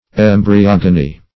embryogony - definition of embryogony - synonyms, pronunciation, spelling from Free Dictionary
Search Result for " embryogony" : The Collaborative International Dictionary of English v.0.48: Embryogony \Em`bry*og"o*ny\, n. [Gr.